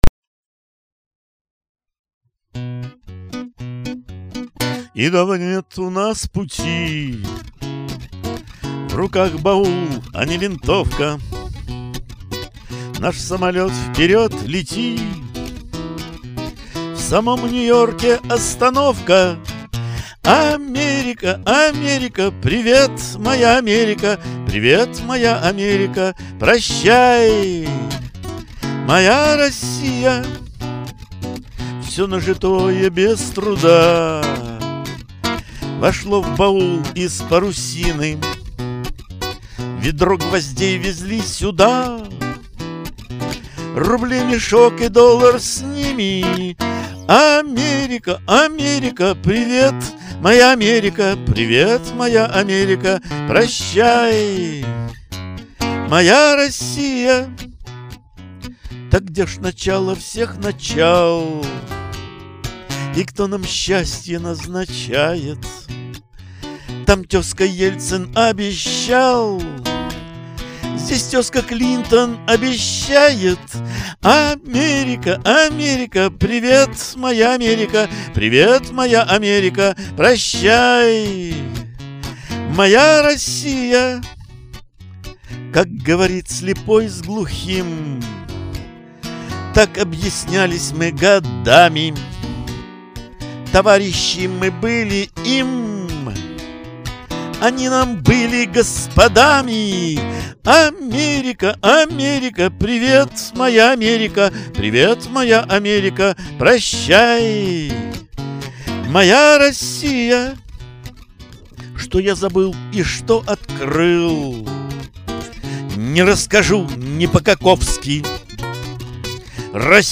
гитара
• Жанр: Авторская песня